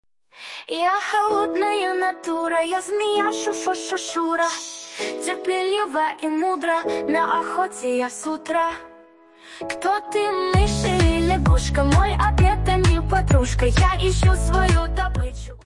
Фрагмент 2-го варианта исполнения (на выход змеи):